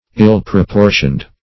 ill-proportioned.mp3